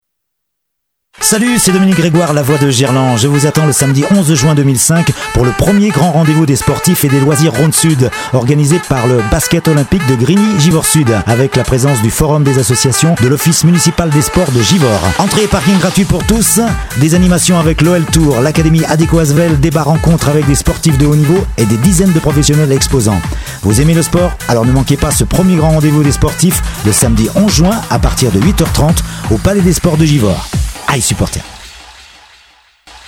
spot publicitaire)